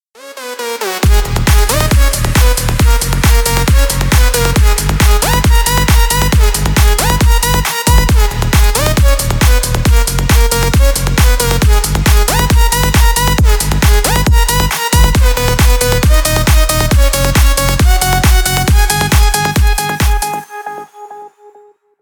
Электроника
клубные # ритмичные # без слов